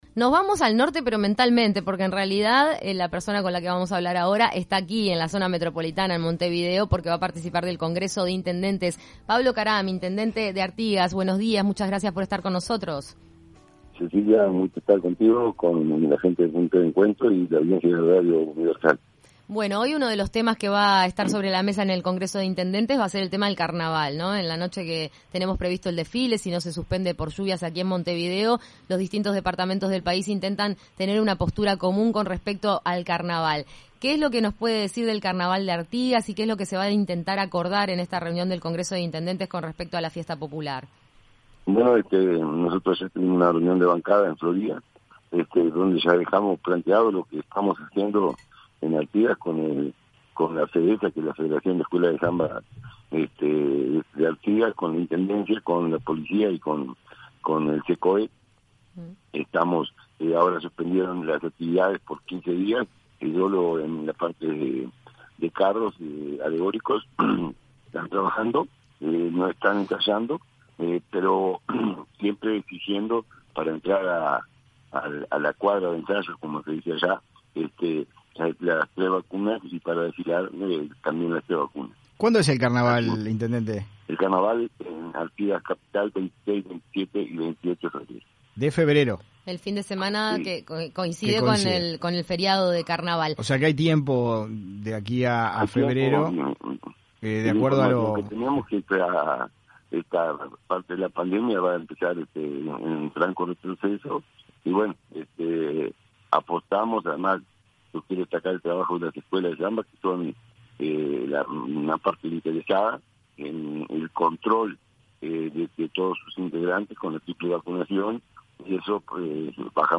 En entrevista en Punto de Encuentro, el intendente, Pablo Caram, apuesta al trabajo de las Escuelas de Samba que controlan la inoculación de sus integrantes.